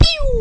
Pew Pew Sound Effect Free Download
Pew Pew